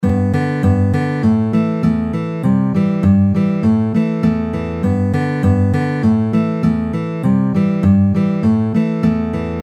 EXAMPLE 4 Chorus
The chords change here into G Am C Am.